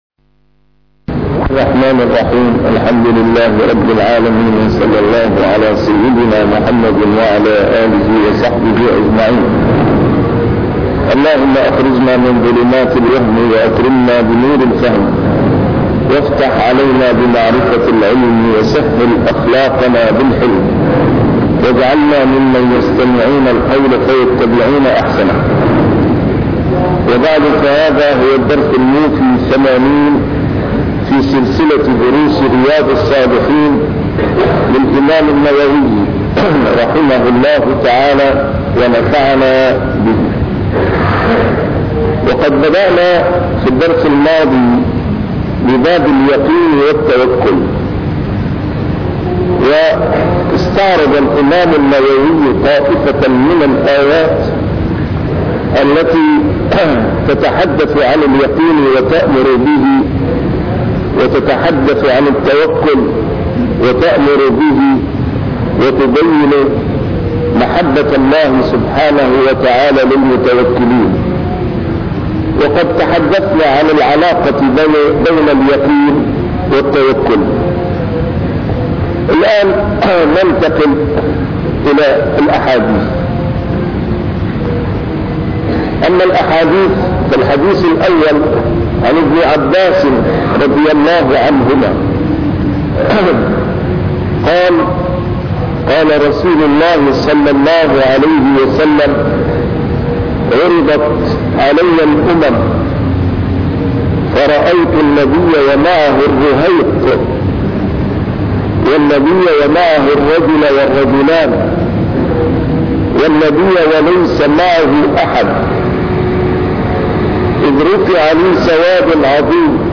A MARTYR SCHOLAR: IMAM MUHAMMAD SAEED RAMADAN AL-BOUTI - الدروس العلمية - شرح كتاب رياض الصالحين - 80- شرح رياض الصالحين: اليقين والتوكل